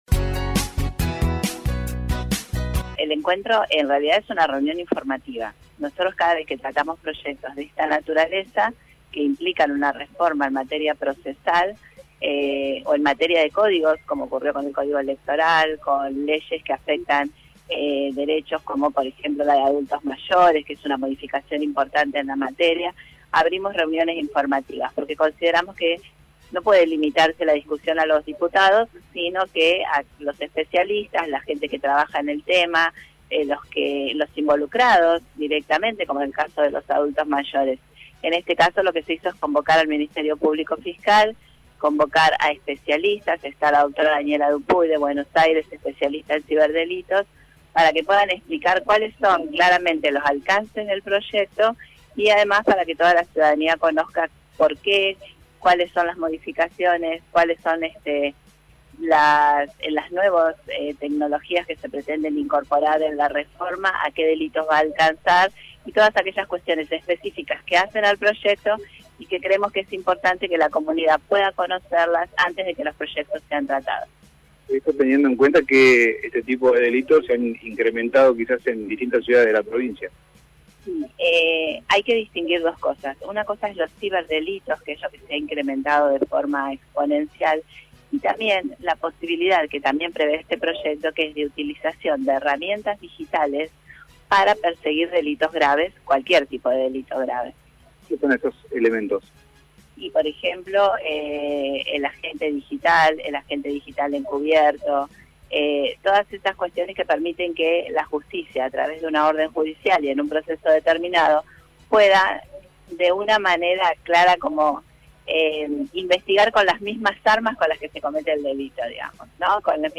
La diputada radical María Andrea Aguilera es la presidenta de la comisión de Asuntos Constitucionales y Justicia y por este tema, dialogó con Radiovisión: